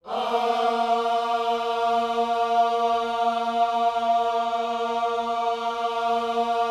OHS A#3E  -L.wav